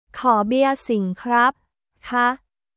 コー ビア シン クラップ／カー